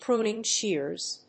アクセントprúning shèars [scìssors]
音節prúning shèars